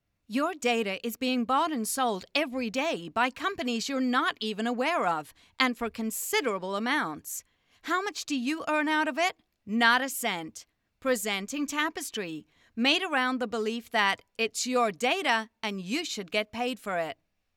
角色旁白-影视游戏